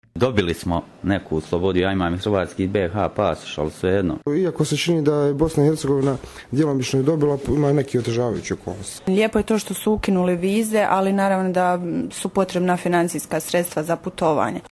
Anketa: Građani Mostara o ukidanju viza